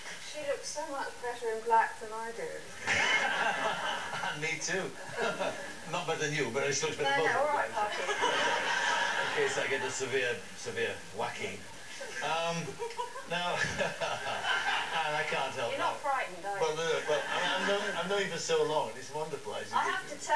HERE ARE SOME OTHER SOUNDS LIKE INTERVIEWS OF WL HOSTS!